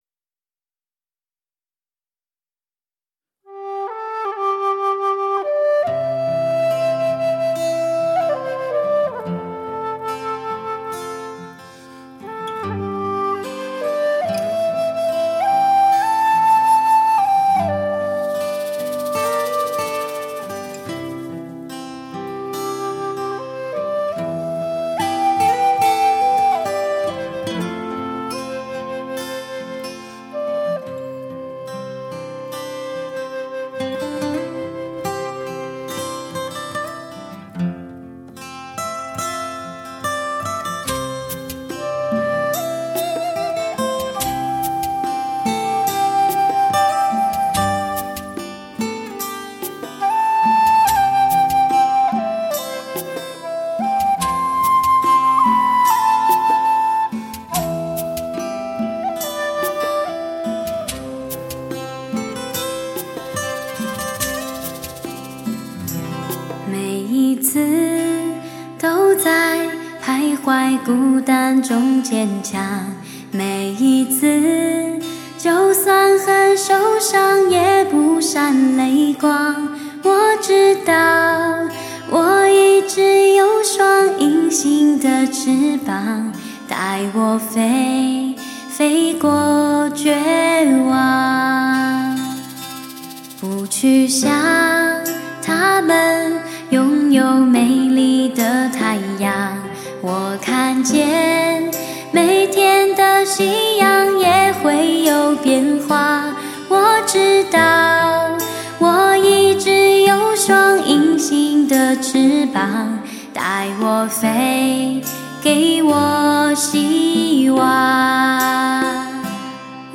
摄人心神的绝佳优嗓 演唱热门的畅销情歌
深刻而知性的诠释 给音乐截然不同的新生命
再加上甜而不腻之嗓音绝对讨好你挑剔的双耳